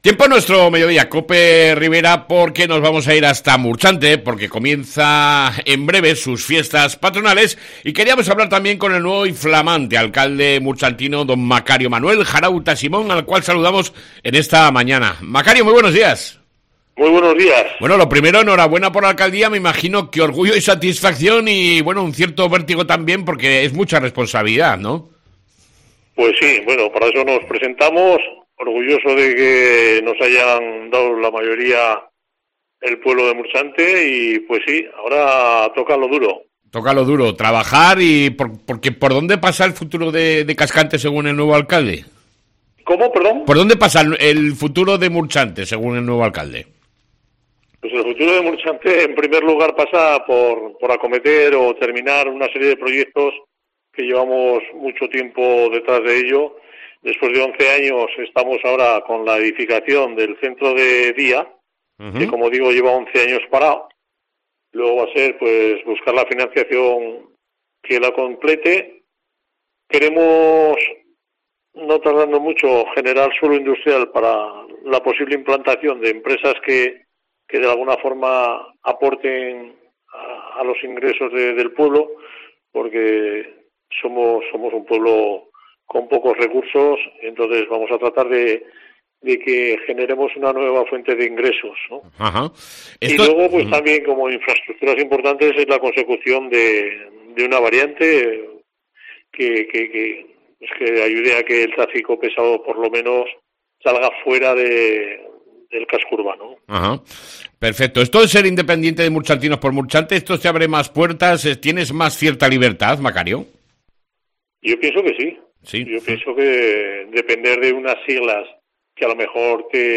ENTREVISTA CON EL ALCALDE DE MURCHANTE, MACARIO JARAUTA SIMÓN